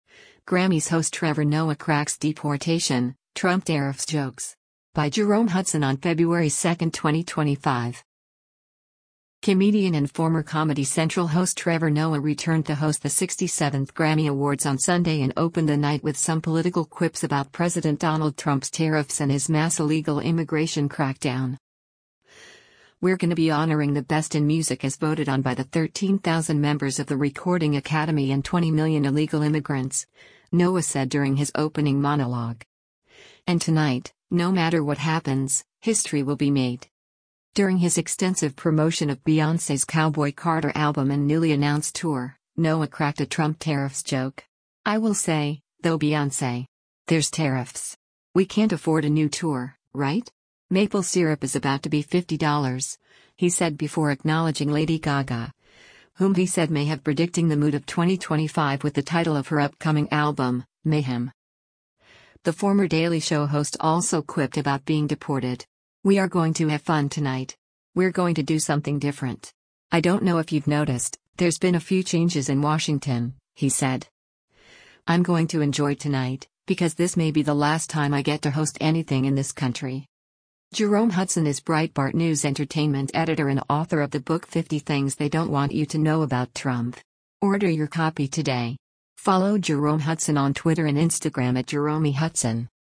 LOS ANGELES, CALIFORNIA - FEBRUARY 02: Host Trevor Noah speaks onstage during the 67th Ann
Comedian and former Comedy Central host Trevor Noah returned to host the 67th Grammy Awards on Sunday and opened the night with some political quips about President Donald Trump’s tariffs and his mass illegal immigration crackdown.